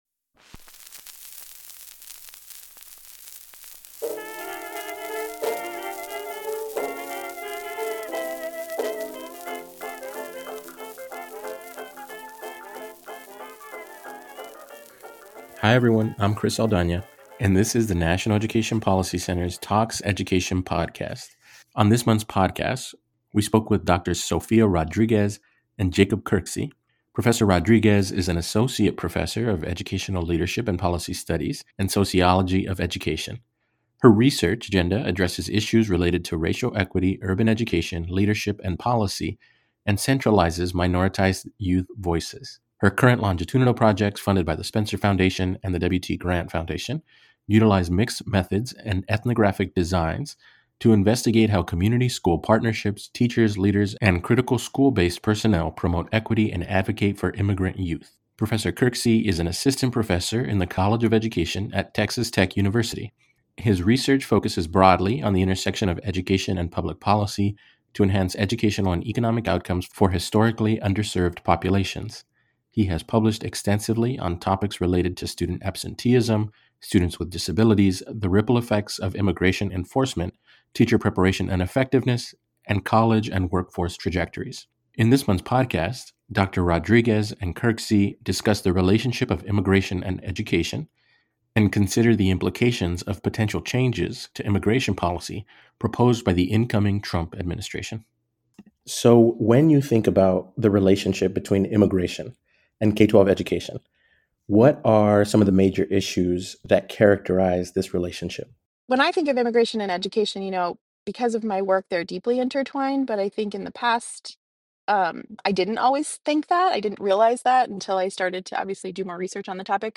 NEPC Talks Education: An Interview